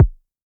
RDM_TapeB_SY1-Kick04.wav